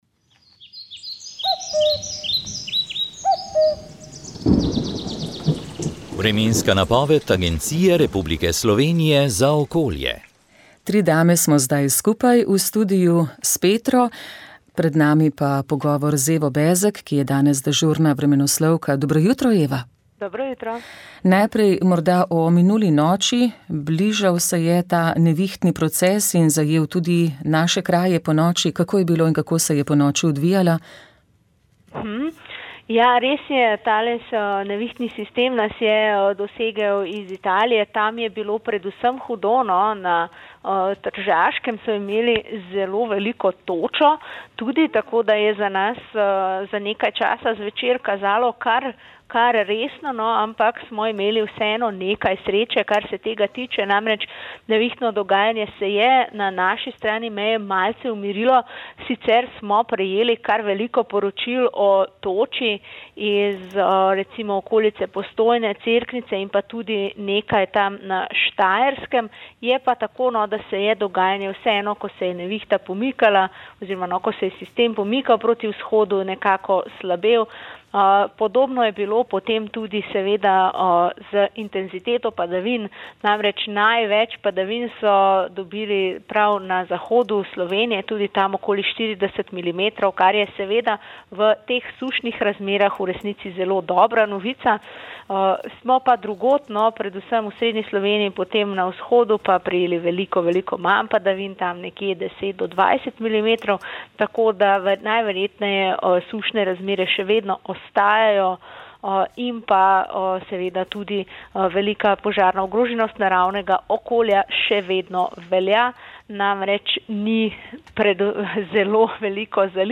Pogledali smo v Vatikan, kjer so kardinali v četrtek izvolili novega papeža, ki si je izbral ime Leon XIV. O izvolitvi, vzdušju, pričakovanjih in še marsičem, smo se pogovarjali